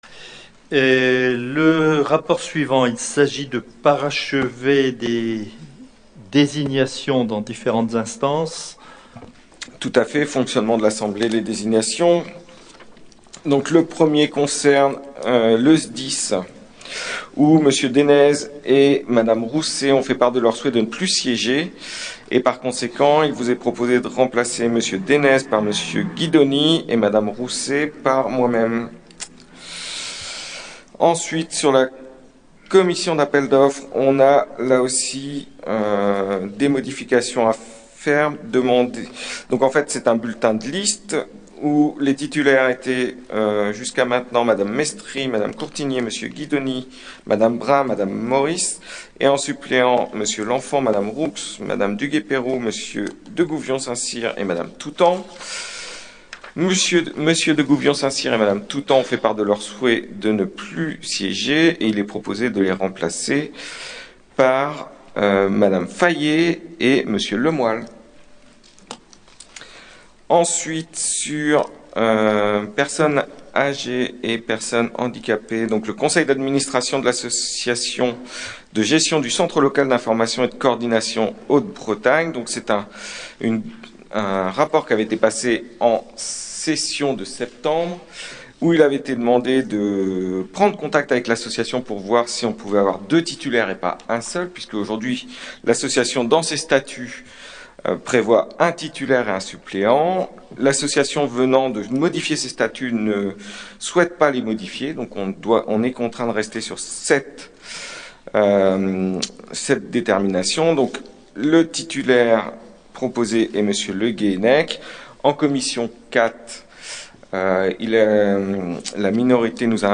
• Assemblée départementale du 17/11/22